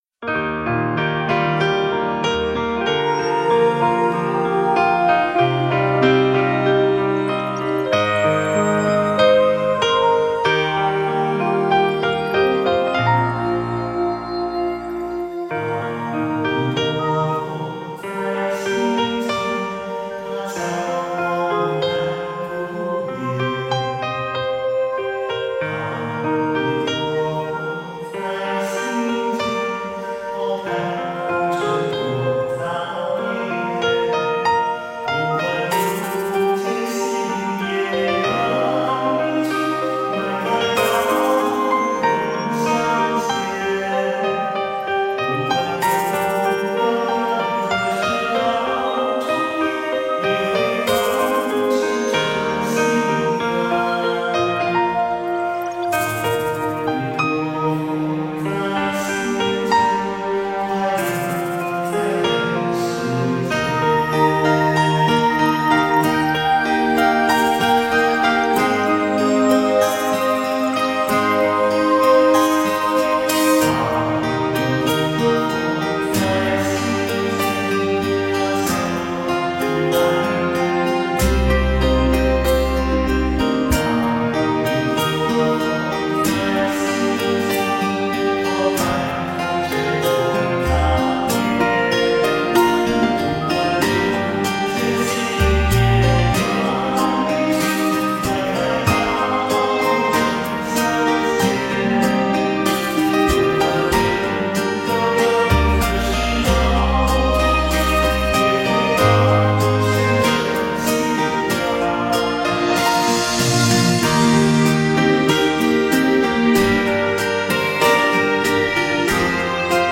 佛音 冥想 佛教音乐 返回列表 上一篇： 佛教音乐-心经--佛歌洞萧纯音乐 菩萨 下一篇： 财神咒(音乐